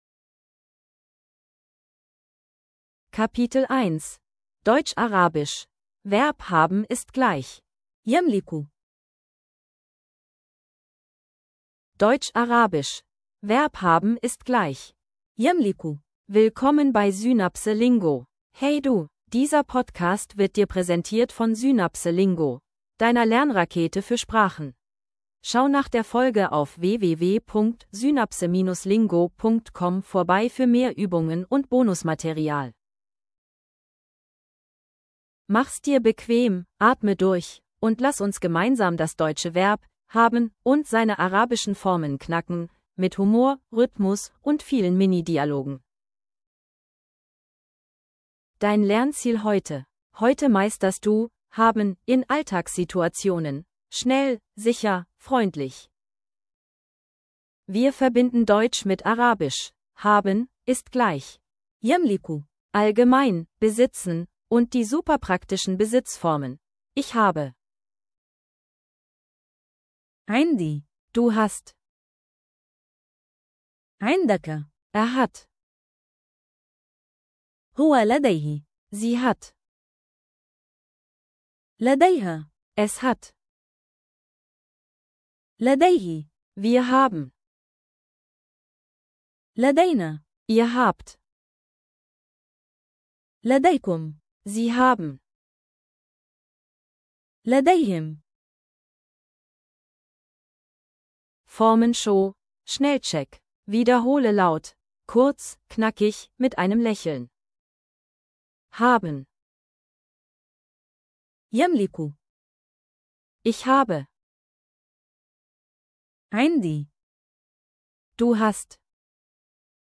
Audio zum Mitsprechen & Wiederholen